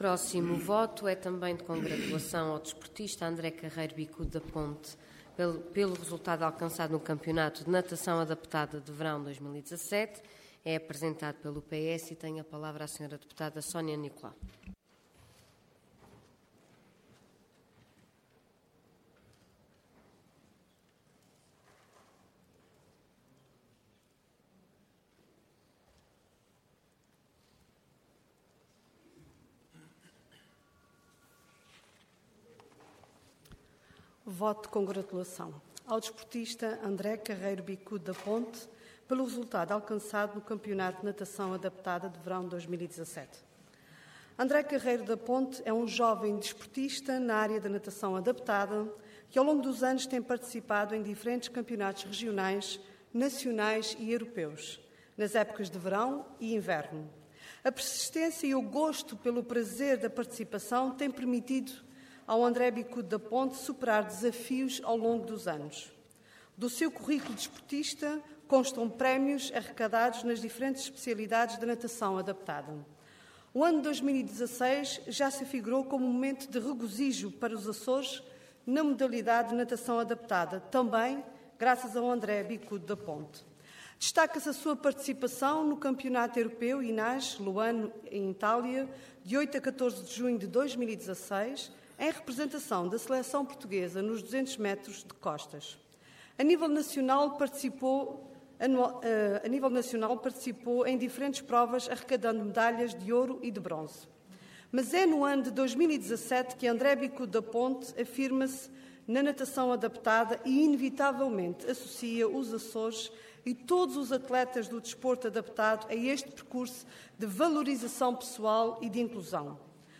Intervenção Voto de Congratulação Orador Sónia Nicolau Cargo Deputada Entidade PS